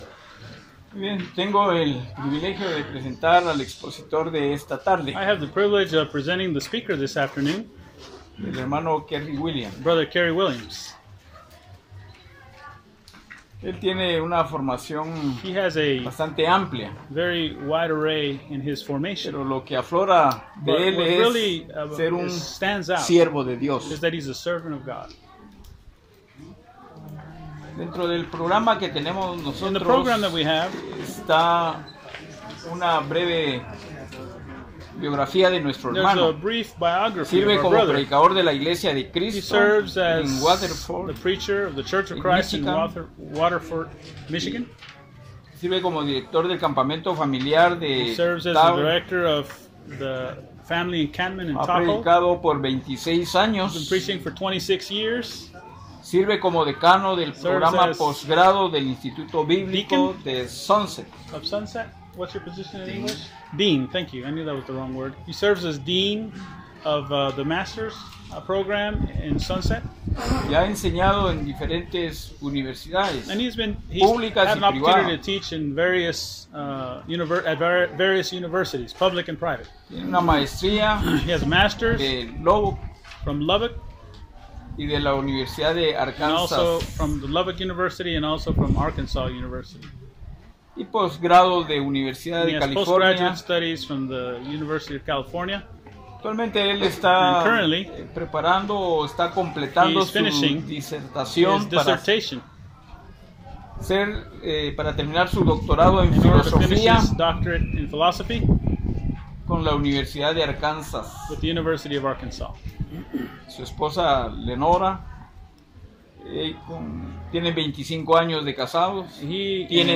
ITL Lectureship 2019
Sermon